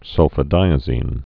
(sŭlfə-dīə-zēn)